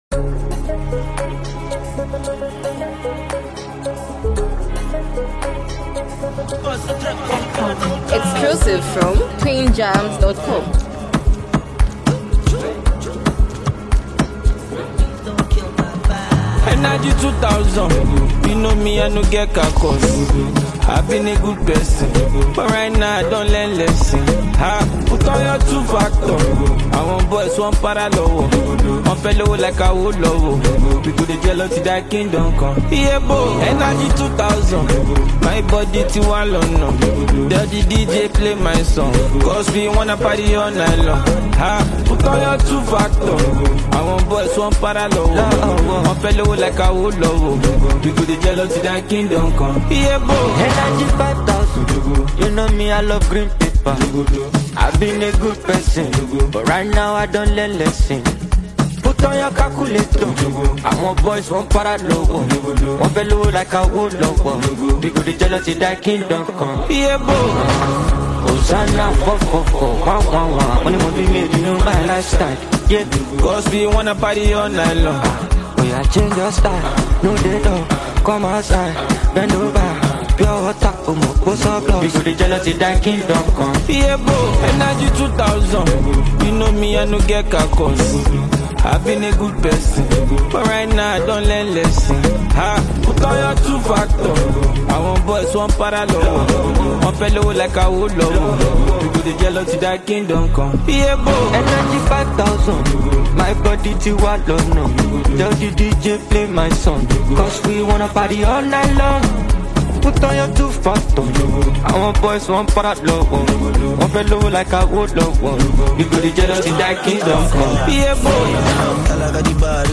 vibrant fusion of Afrobeats and Amapiano